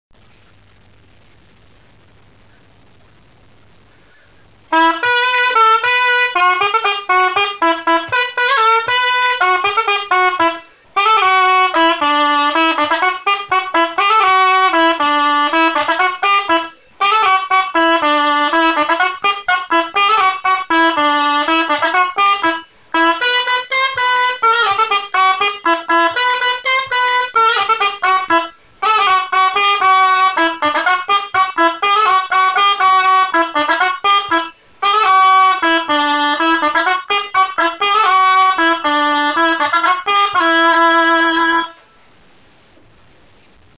Genre : morceau instrumental Instrument de musique : aboès Danse : bourrée